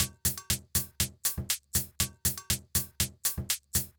Drumloop 120bpm 06-B.wav